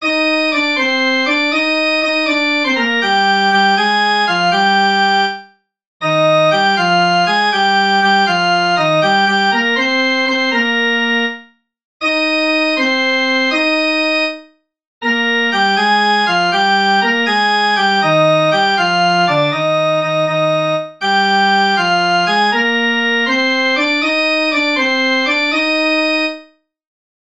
(The wives dance in a little circle and sing.)